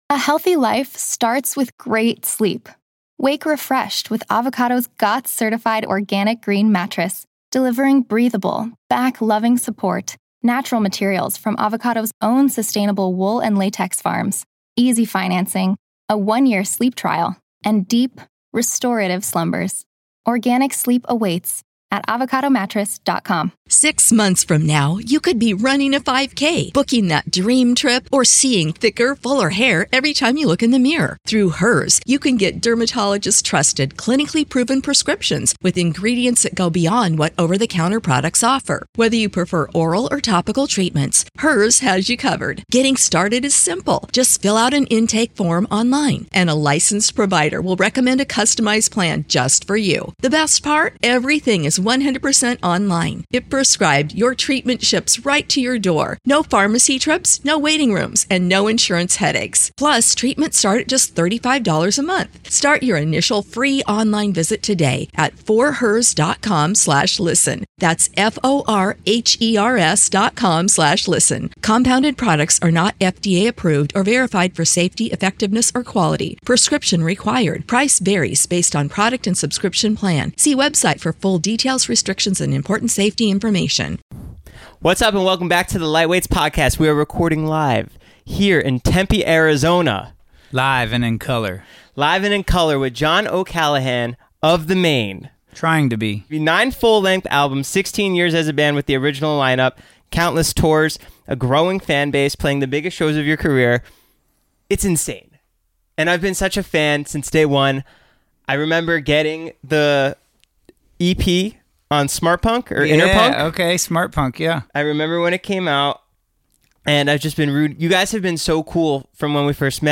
Society & Culture, Tv Reviews, Comedy Interviews, Film Interviews, Comedy, Tv & Film, Education, Hobbies, Music Commentary, Music Interviews, Relationships, Leisure, Health & Fitness, Self-improvement, Music, Fitness